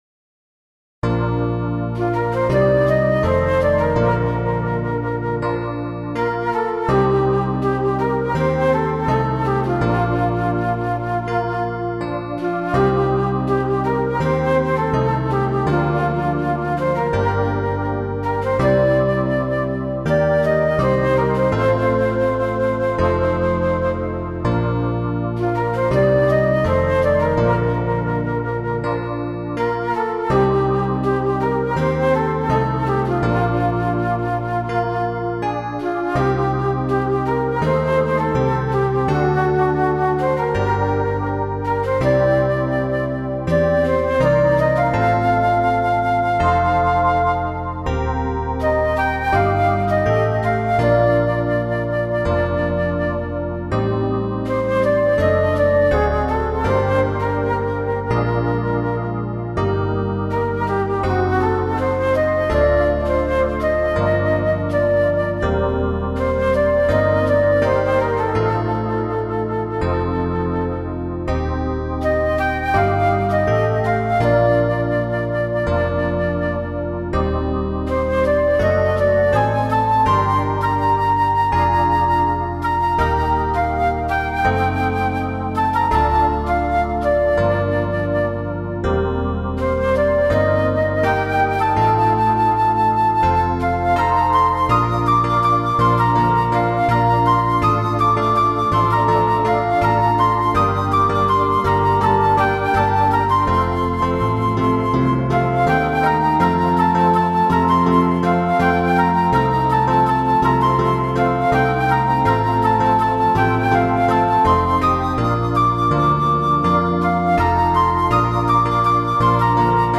インストゥルメンタルロング明るい穏やか